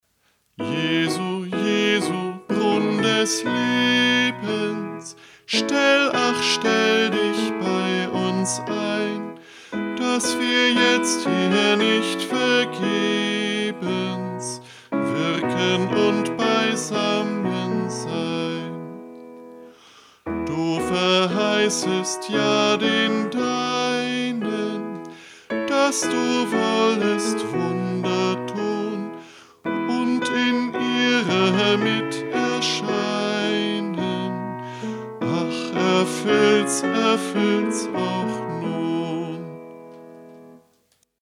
Liedvortrag